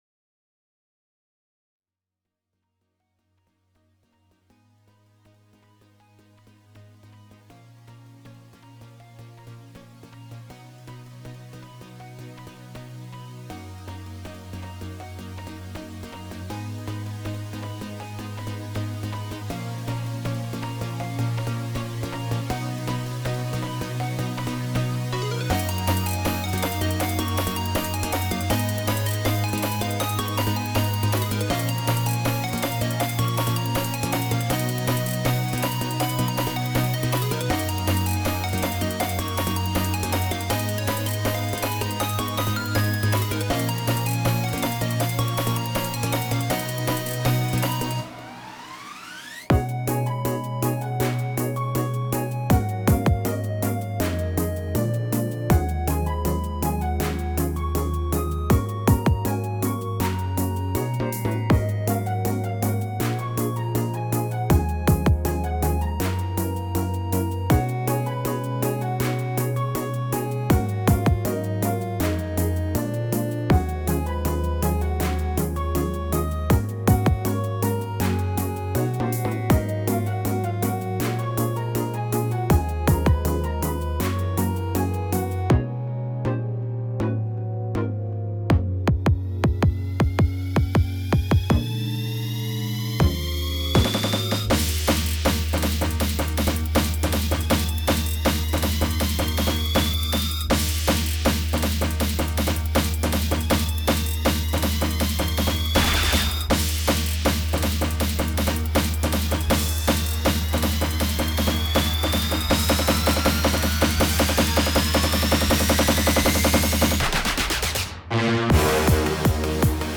BGM
風を切り裂き、大地を蹴り上げるような疾走感！爆発的なスピード感と興奮をもたらします。